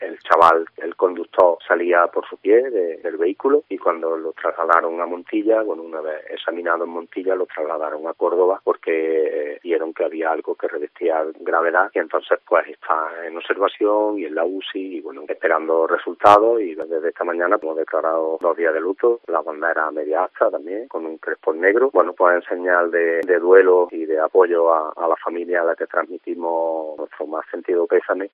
Escucha al alcalde de Fernán Núñez, Alfonso Alcaide